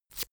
Royalty free sounds: Paper